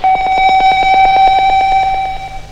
• DECREASING BEEPING.wav
DECREASING_BEEPING_z7s.wav